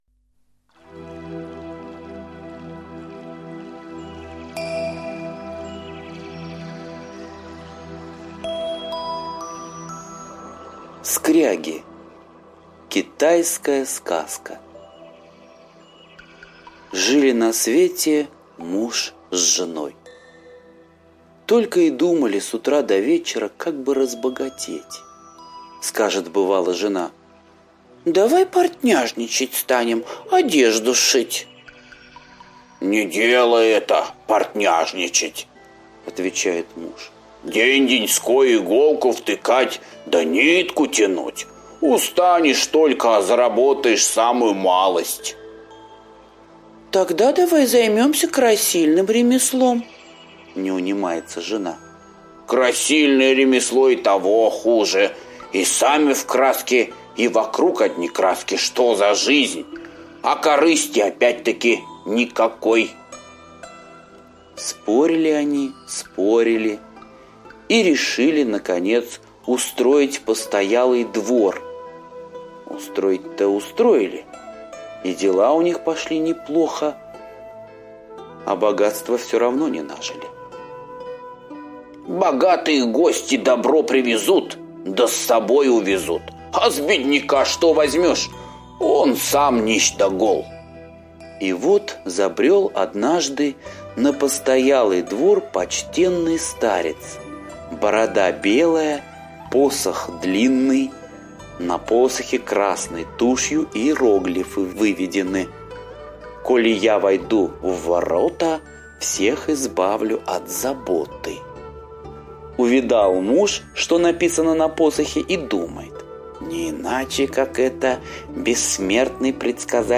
Скряги – азиатская аудиосказка